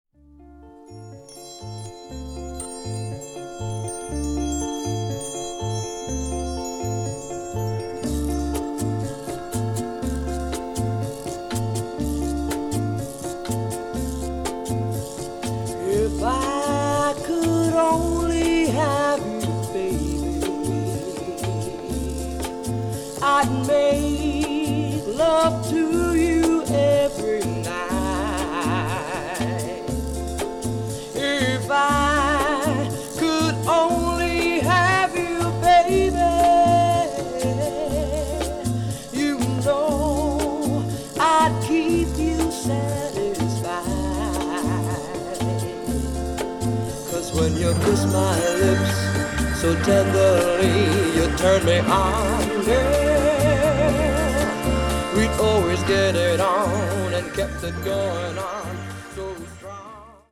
Classic Latin Funk